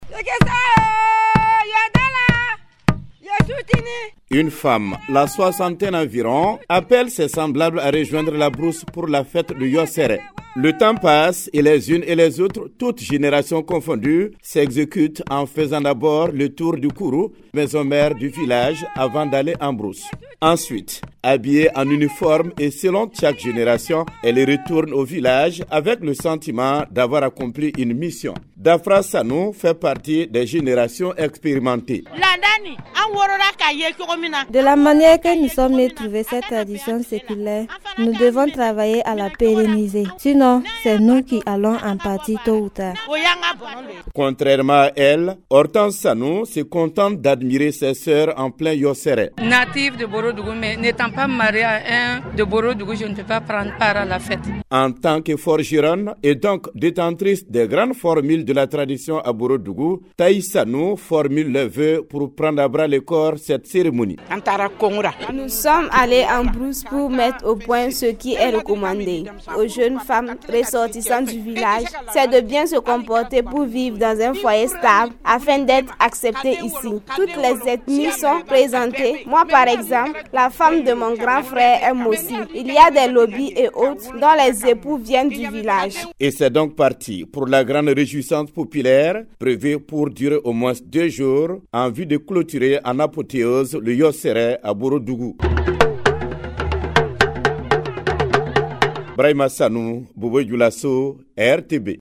De Bobo Dioulasso, reportage